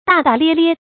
大大咧咧 注音： ㄉㄚˋ ㄉㄚˋ ㄌㄧㄝ ㄌㄧㄝ 讀音讀法： 意思解釋： 形容待人處事隨隨便便，滿不在乎。